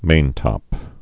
(māntŏp)